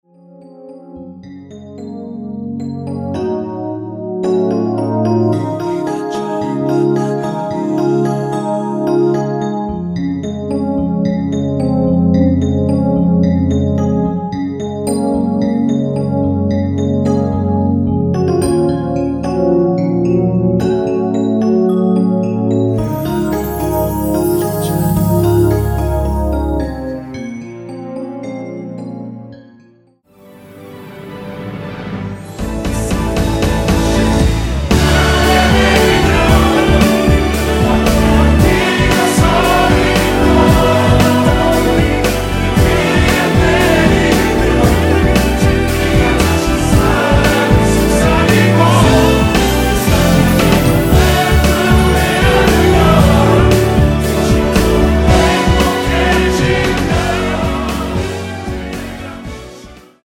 (-2) 내린 코러스 포함된 MR이며 랩은 없습니다.(미리듣기 참조)
Db
앞부분30초, 뒷부분30초씩 편집해서 올려 드리고 있습니다.
중간에 음이 끈어지고 다시 나오는 이유는